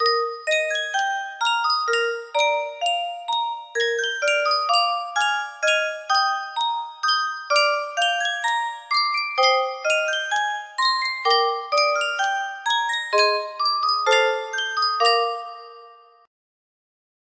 Yunsheng Music Box - Vivaldi The Four Seasons Winter 4418 music box melody
Full range 60